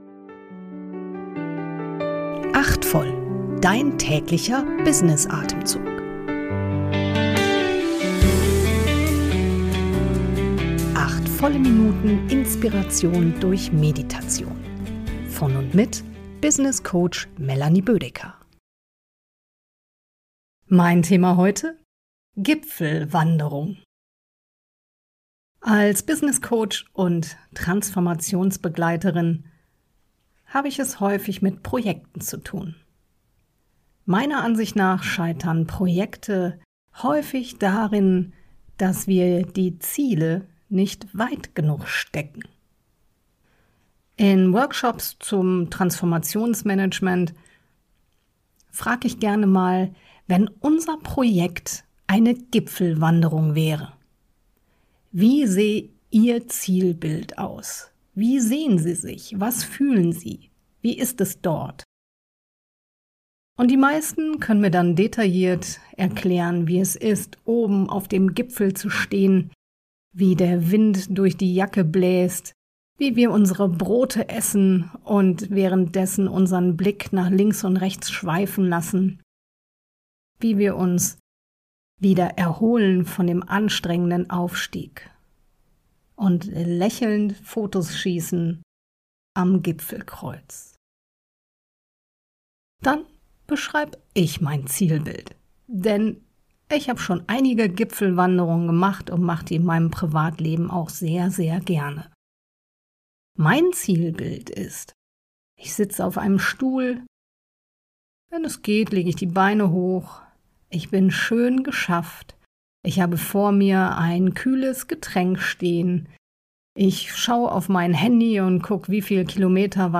Erfrischung durch eine geleitete Kurz-Meditation.